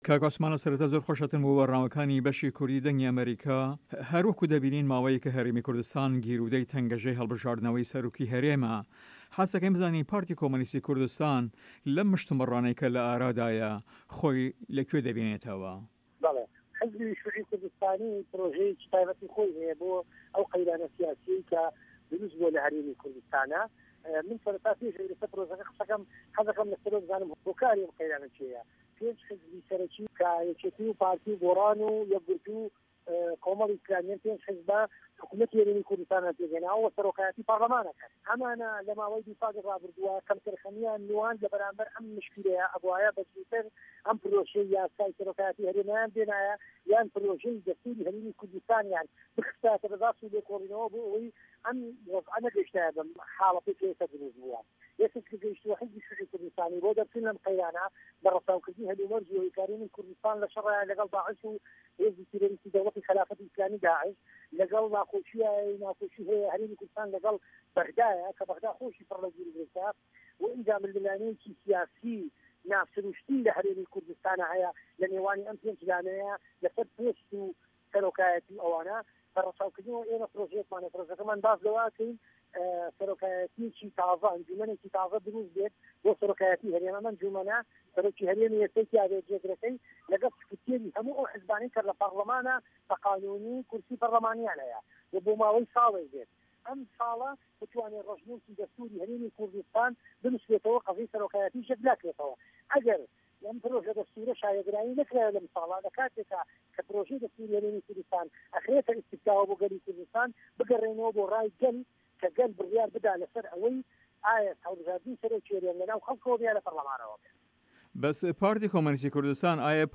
لقی سلێمانی لە هەڤپەیڤینێکدا لەگەڵ بەشی کوردی دەنگی ئەمەریکا دەڵێت" بە ڕەچاوکردنی ئەو هەل و مەرجەی لە هەرێم دا هاتوەتە ئاراوە ئێمە پرۆژەیەکمان هەیە کە باس لە سەرۆکایەتیەکی تازە دەکات، ئەنجومەنێکی تازە درووست بێت بۆ سەرۆکایەتی هەرێم، ئەم ئەنجومەنە سەرۆکی ئێستای هەرێم و جێگرەکەی تێدا بێ و لەگەڵ سکرتێری هەموو ئەو حیزبانەی لە پەرلەماندا بە یاسایی کورسی پەرلەمانیان هەیە وە بۆ ماوەی ساڵێک بێت.